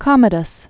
(kŏmə-dəs), Lucius Aelius Aurelius AD 161-192.